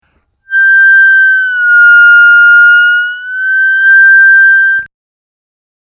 На 8-ом, 9-ом и 10-ом отверстиях понижать ноту до самого дна, возвращаясь затем к чистой ноте.